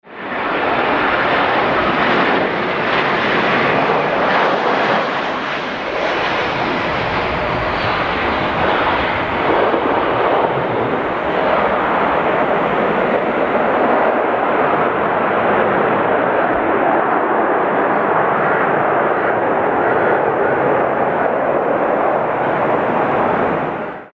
Посадка Boeing 737
posadka-boing-737.mp3